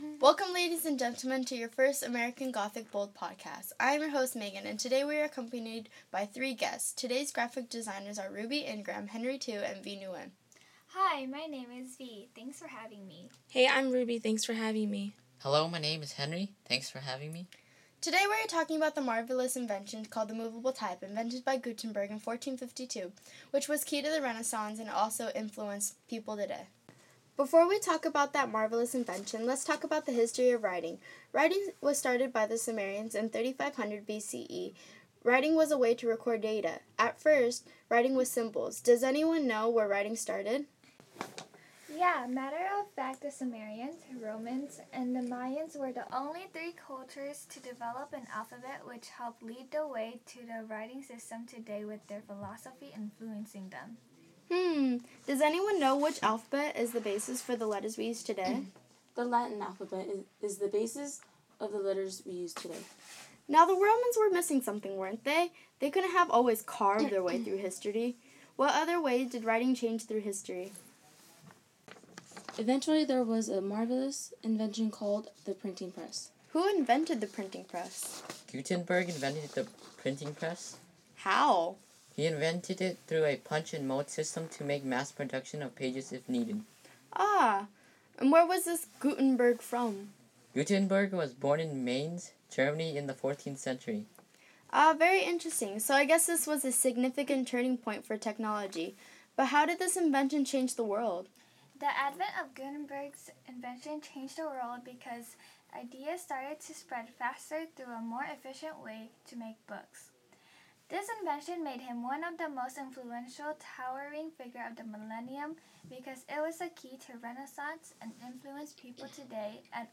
Printing press